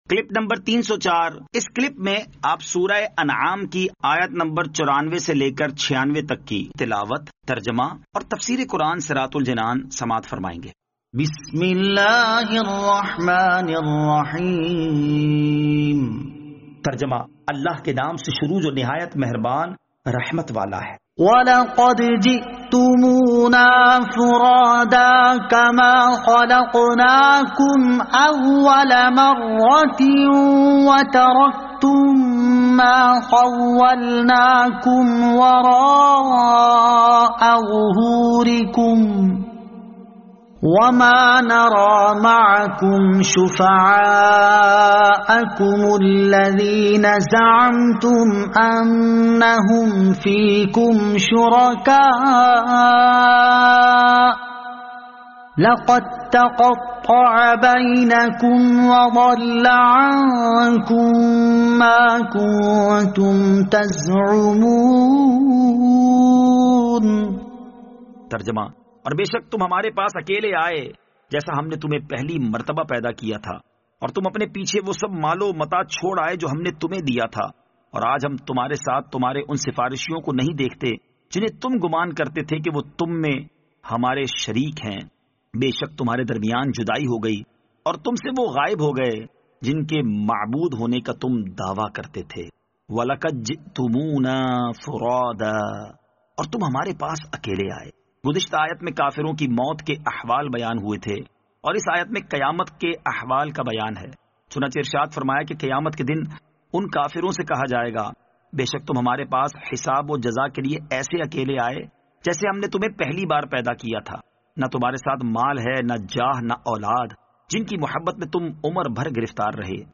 Surah Al-Anaam Ayat 94 To 96 Tilawat , Tarjama , Tafseer